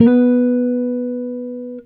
Guitar Slid Octave 12-B2.wav